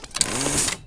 mine.deploy.WAV